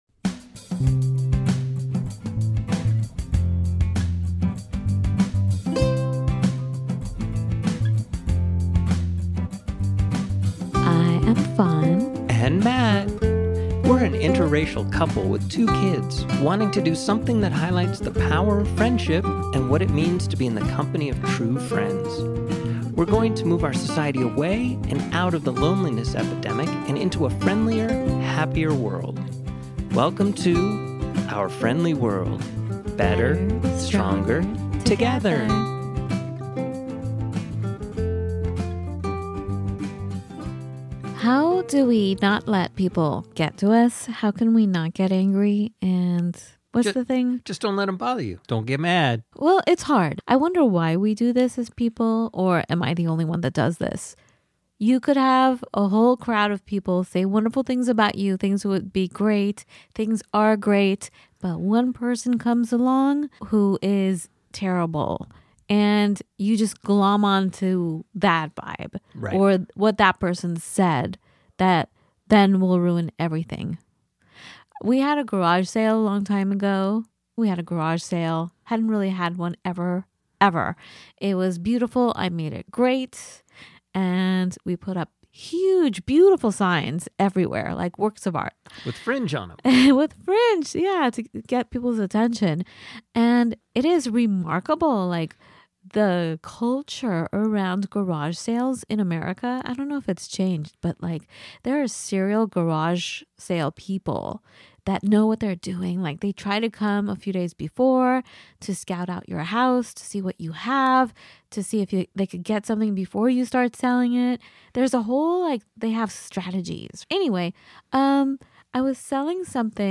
This raw, honest conversation challenges listeners to question where their reactions come from and how to find peace in the chaos of modern relationships.